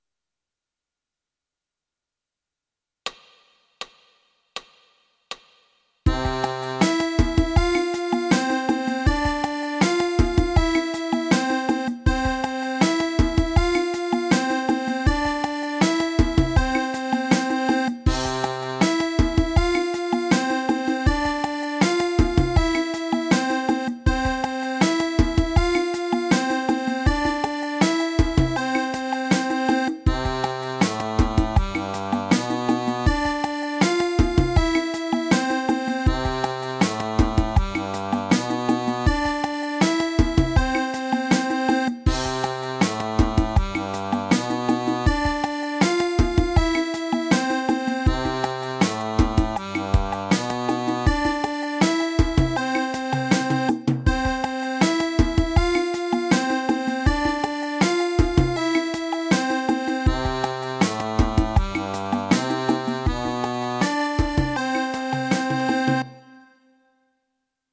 scala Misolidia